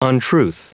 Prononciation du mot untruth en anglais (fichier audio)
Prononciation du mot : untruth